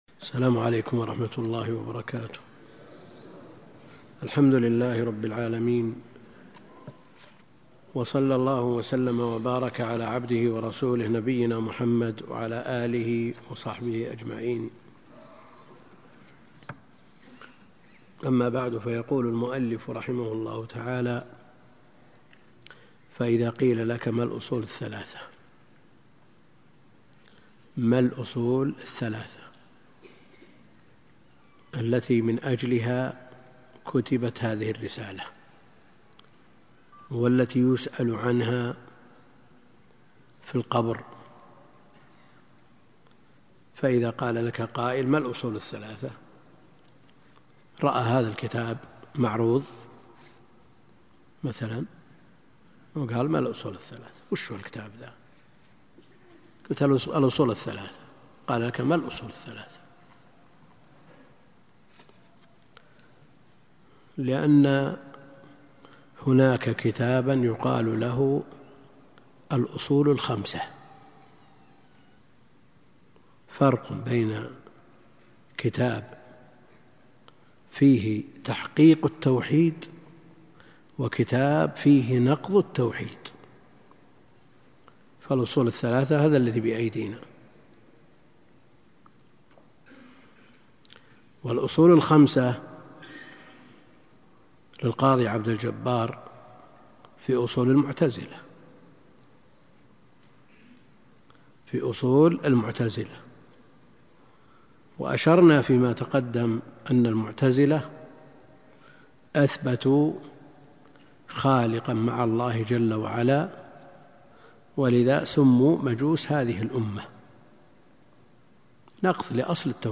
تفاصيل المادة عنوان المادة الدرس (3) شرح الأصول الثلاثة تاريخ التحميل السبت 14 يناير 2023 مـ حجم المادة 35.93 ميجا بايت عدد الزيارات 224 زيارة عدد مرات الحفظ 103 مرة إستماع المادة حفظ المادة اضف تعليقك أرسل لصديق